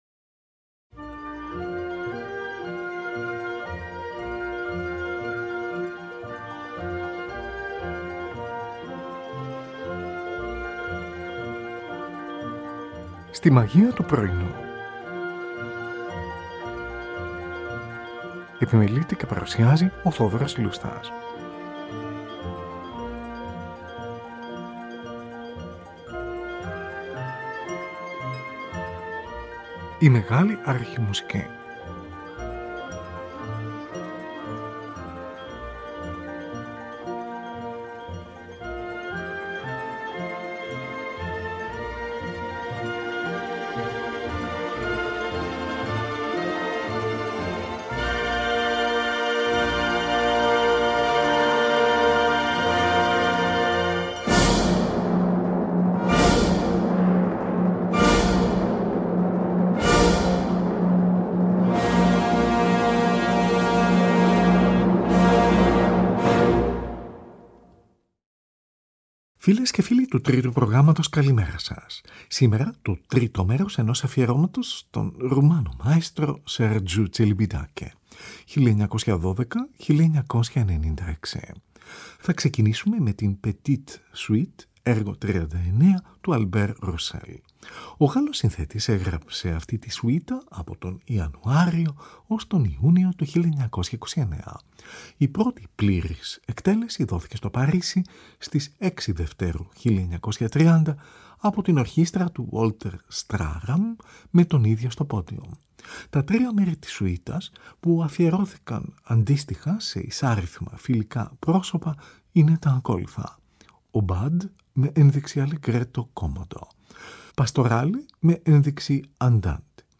Τη Φιλαρμονική του Μονάχου διευθύνει ο Sergiu Celibidache, από ζωντανή ηχογράφηση, στις 19 ή 20 Μαρτίου 1987 .